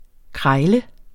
Udtale [ ˈkʁɑjlə ]